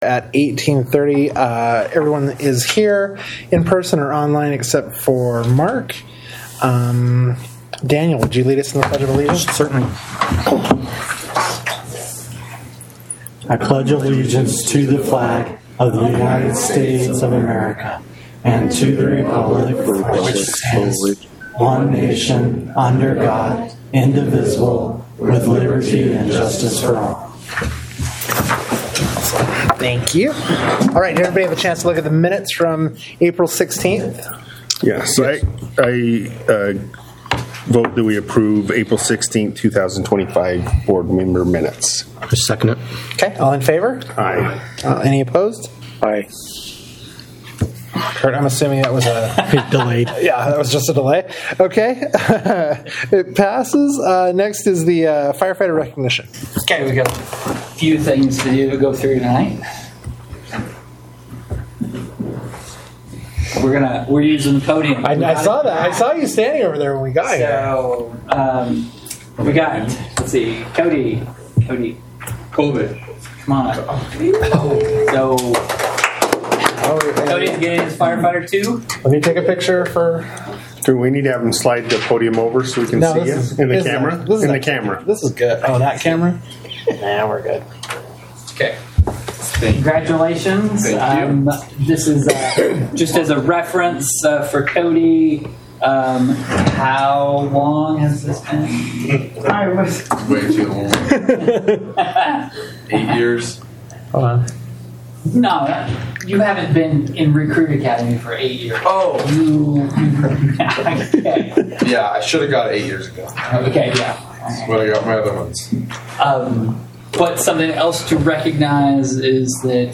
Board Meeting
Notice is hereby given that the North Tooele Fire Protection Service District will hold a board meeting on May 21, 2025, at 6:30 p.m. at the Stansbury Park Fire Station, 179 Country Club, Stansbury Park, UT.